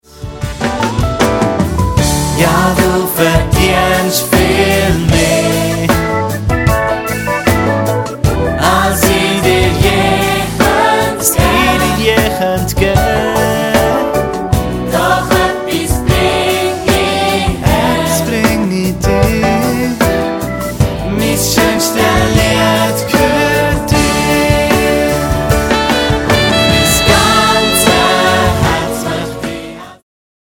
Mundart-Worshipsongs